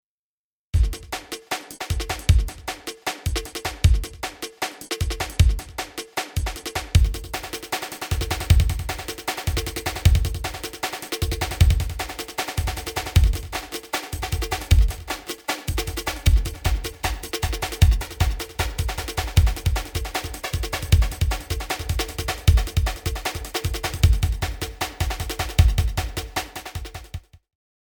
ディレイディレイ
音に「遅れ」を発生させるエフェクト。
delay.mp3